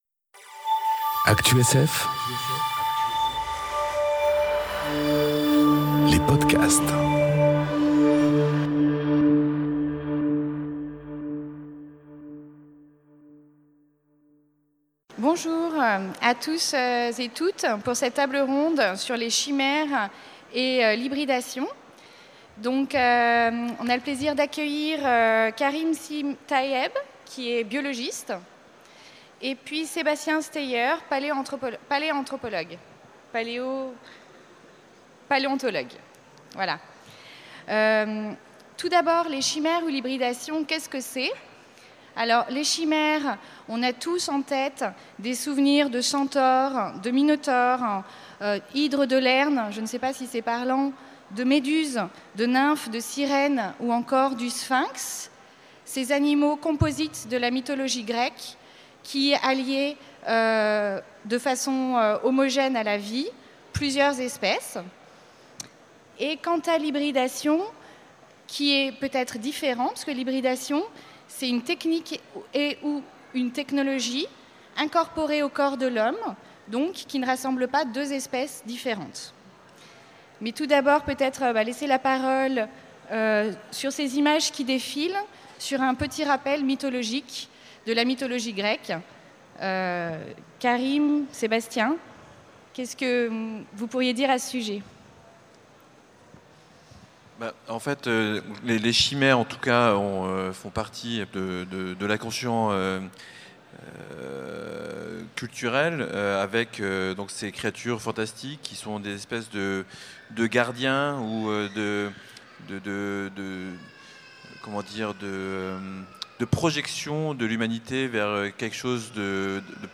Conférence Les chimères ou l'hybridation enregistrée aux Utopiales 2018